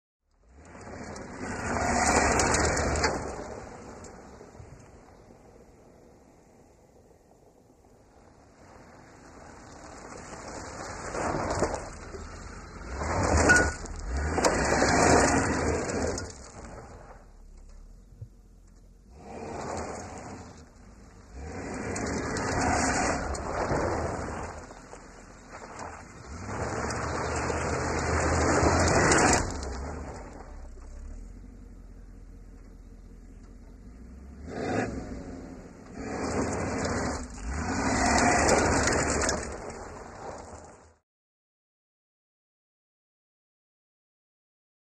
Car, Performing Maneuvers On Dirt, with Suspension Rattles, Quick Starts & Stops, Fast Acceleration & Swerving, Some Gravel Hitting Car.